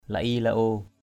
/la-i-la-o:/ (cv.) li-i-li-o l{i}-l{o%